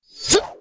swordman_attack12.wav